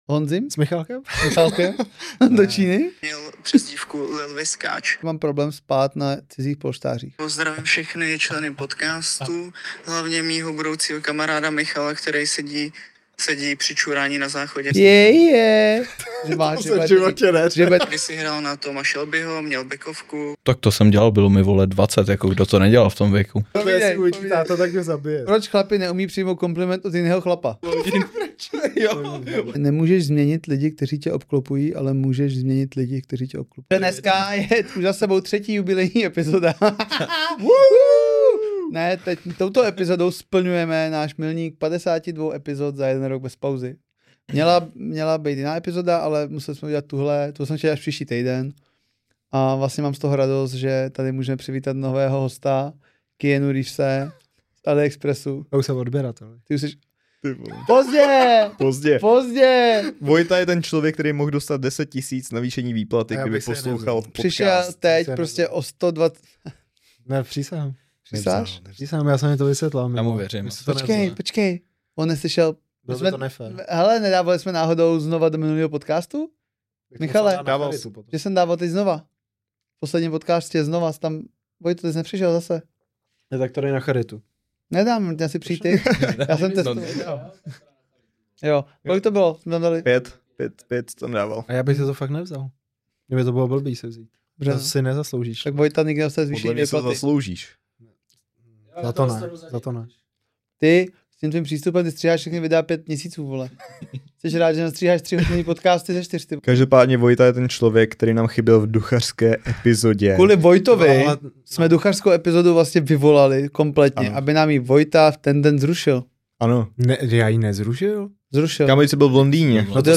Prostě 3 káři v té nejlepší formě – autentický, uvolněný a s hláškama, který nás položily.